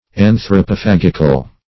Search Result for " anthropophagical" : The Collaborative International Dictionary of English v.0.48: Anthropophagic \An`thro*po*phag"ic\, Anthropophagical \An`thro*po*phag"ic*al\, a. Relating to cannibalism or anthropophagy.